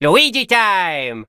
In Super Mario Party Jamboree, Luigi answers the age old question, "What time is it?"